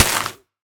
Minecraft Version Minecraft Version snapshot Latest Release | Latest Snapshot snapshot / assets / minecraft / sounds / block / muddy_mangrove_roots / break4.ogg Compare With Compare With Latest Release | Latest Snapshot
break4.ogg